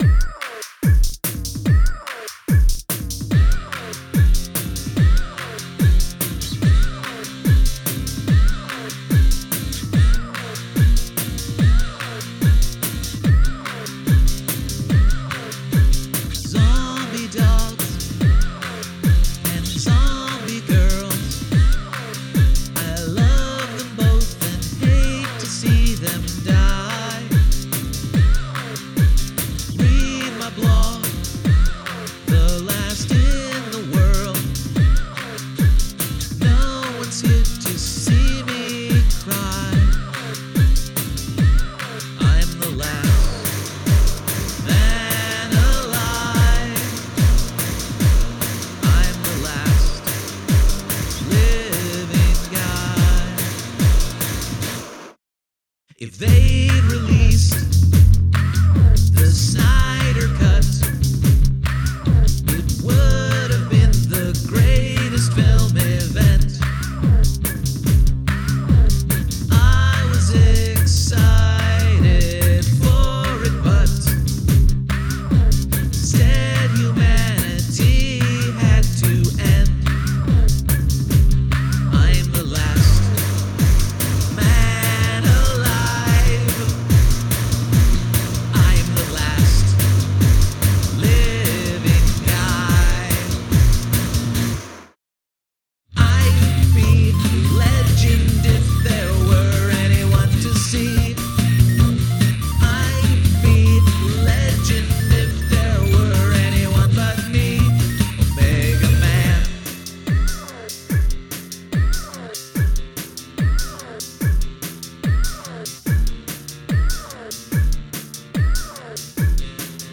I Am Legend - An accidentally timely glitch punk original
Interesting decision to push the guitar so far to the left and so far back.
The song ebbs and flows nicely and builds really well.
You mean the bleepy synth that comes in on the choruses?
You might want it that way, but the thumping bass line seems pretty up front.
The bass drum sound? Yeah, it's a synth. (Or rather a VSTi emulation of a synthesis drum machine).